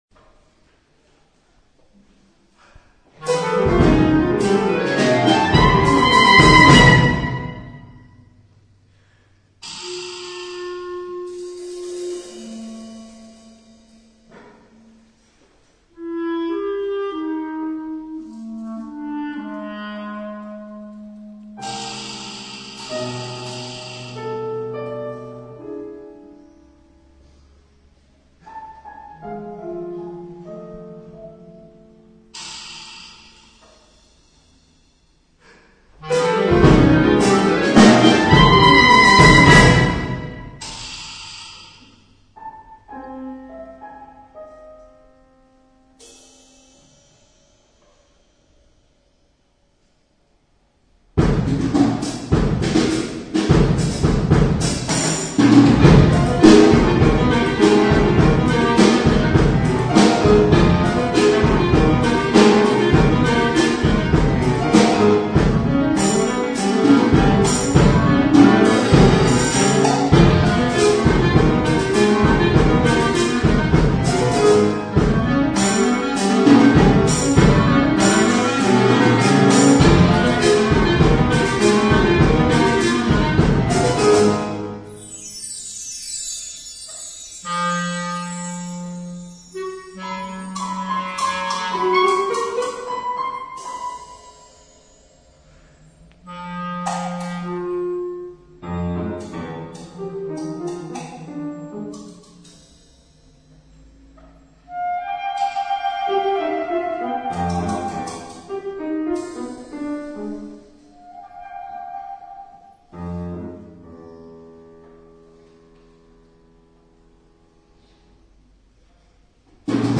Per clarinetto, percussioni e pianoforte